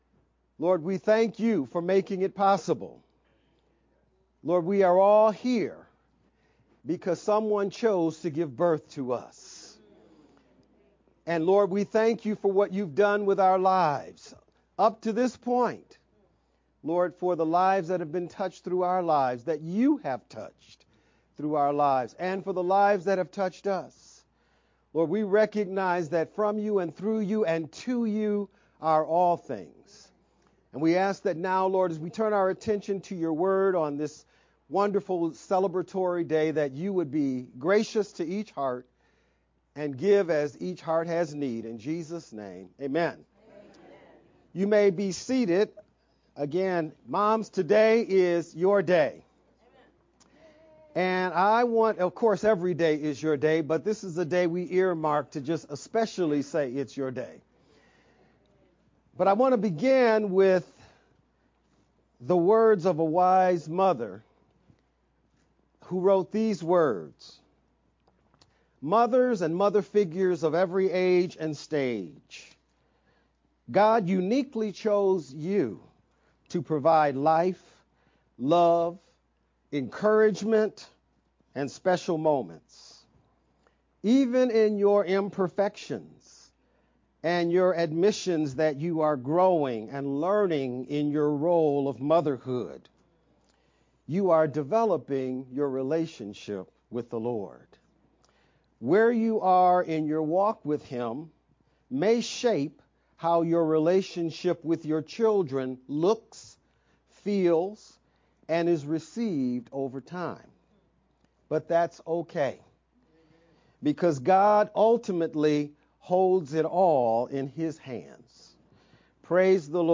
VBCC-Sermon-only_Converted-CD.mp3